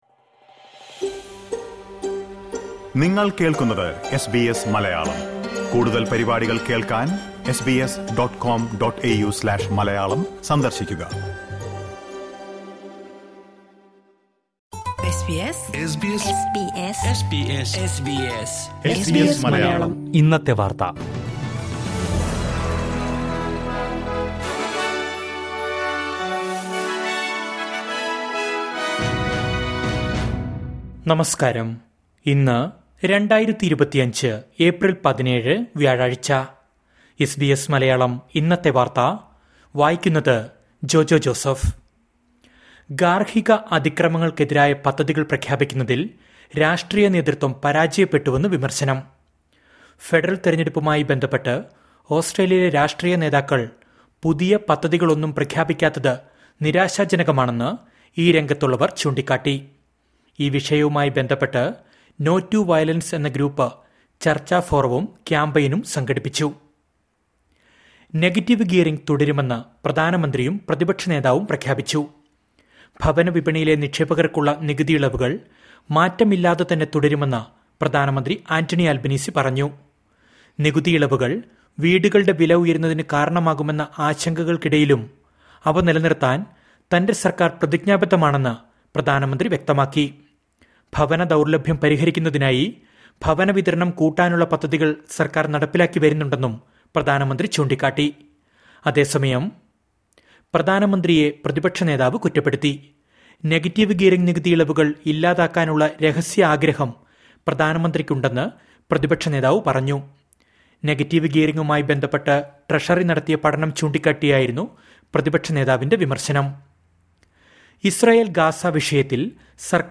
2025 ഏപ്രില്‍ 17ലെ ഓസ്‌ട്രേലിയയിലെ ഏറ്റവും പ്രധാന വാര്‍ത്തകള്‍ കേള്‍ക്കാം...